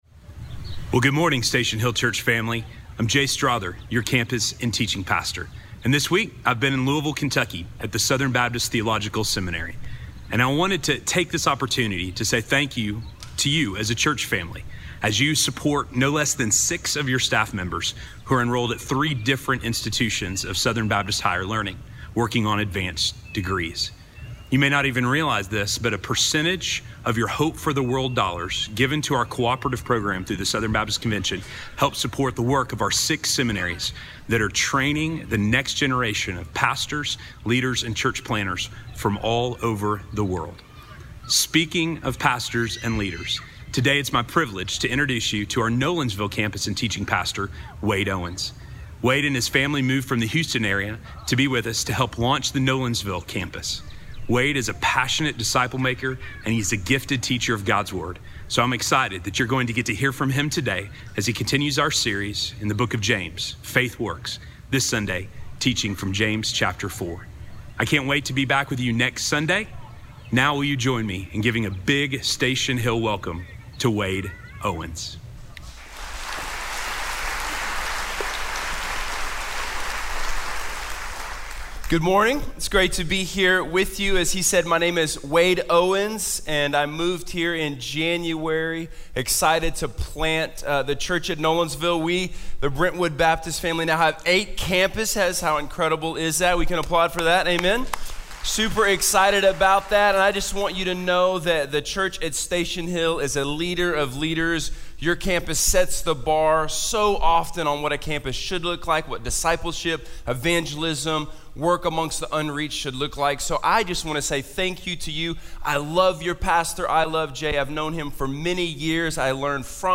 Faith Contrasted - Sermon - Station Hill